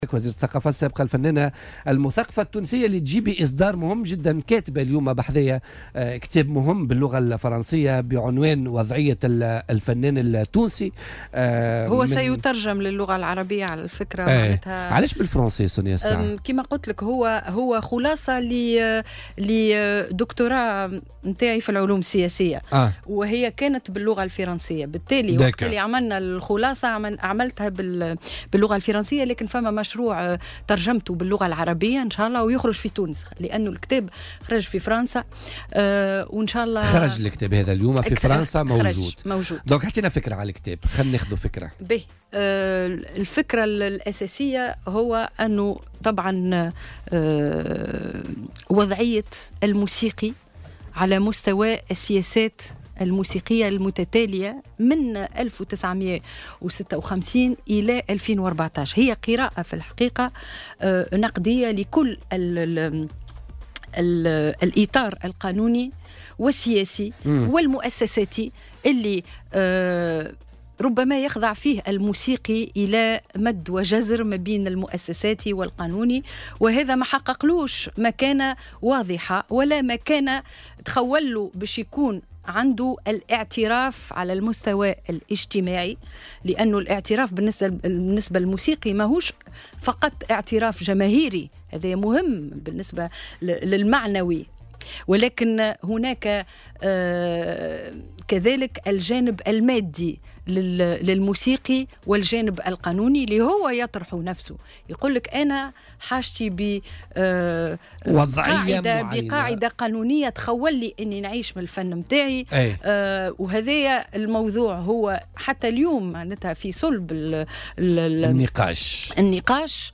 وأضافت ضيفة "بوليتيكا" على "الجوهرة اف أم" أنه يمكن الاستفادة من هذا الصرح الثقافي من خلال تغيير نظرتنا للممارسات الثقافية بالأساس، وذلك في تعليقها على الانطلاق الفعلي لنشاط هذا المشروع الثقافي.